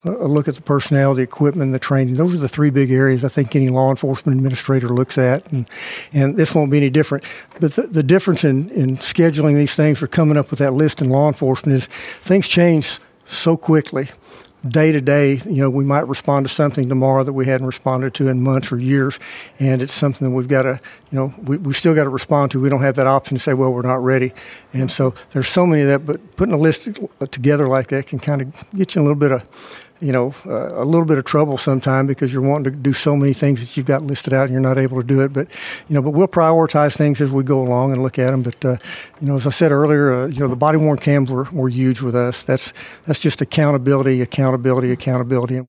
KTLO, Classic Hits and The Boot News spoke with Sheriff elect Hollingsworth live at the Baxter County Courthouse Tuesday evening following the news of his victory.